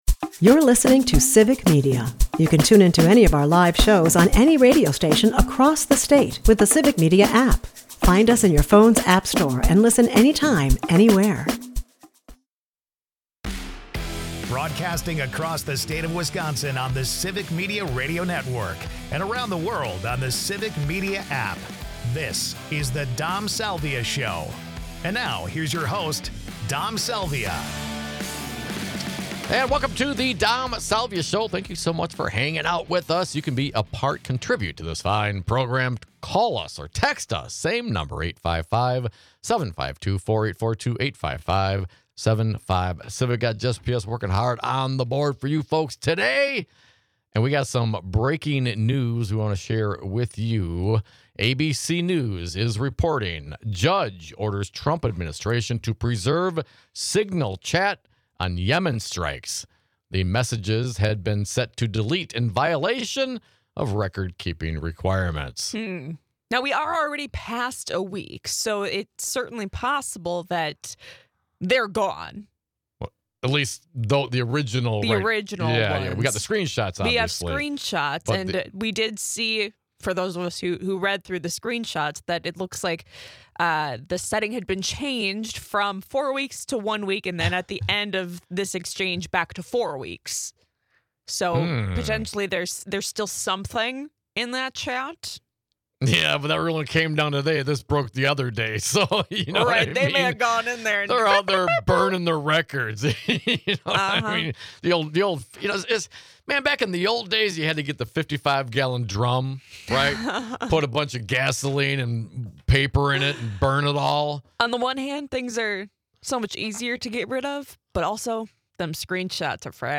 And a caller shares his experience just this week with the IRS in this vein.